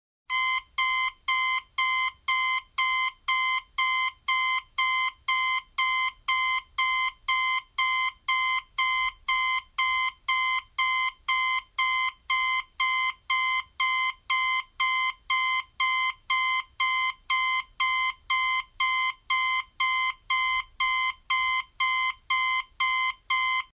alarm clock